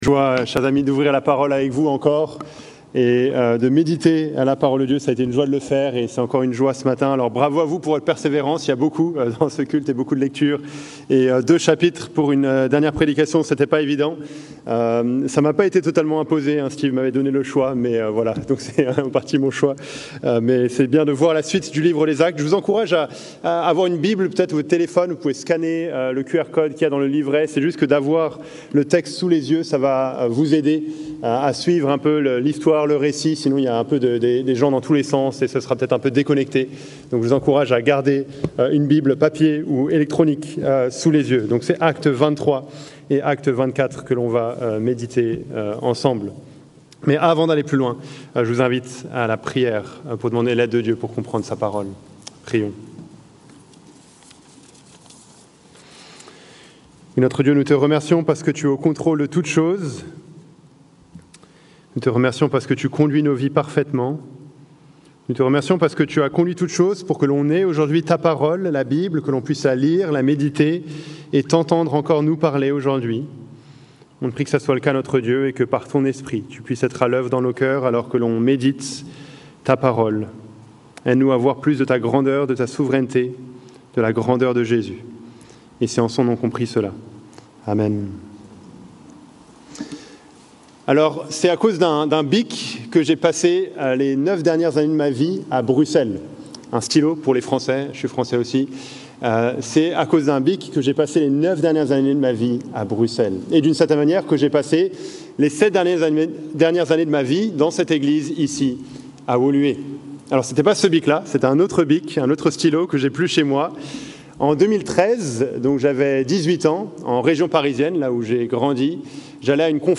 Predication_July-6th-2025_audio.mp3